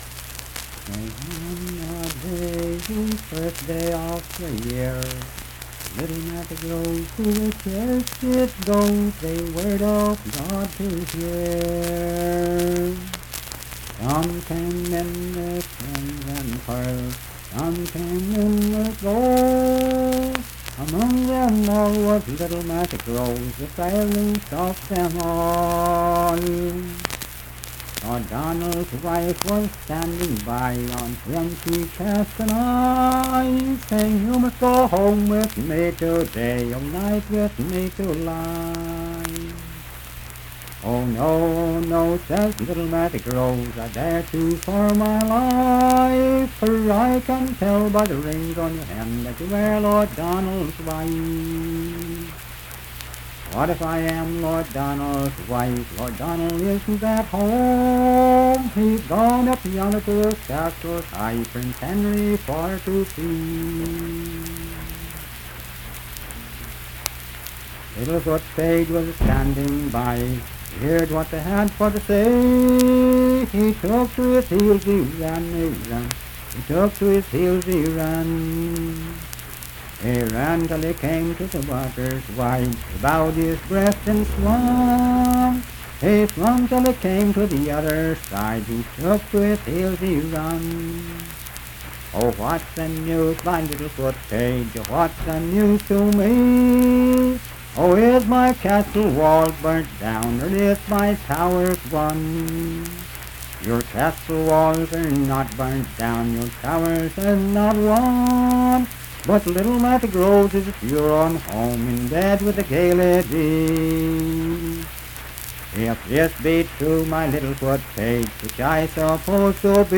Unaccompanied vocal music
Performed in Bentree, Clay County, WV.
Voice (sung)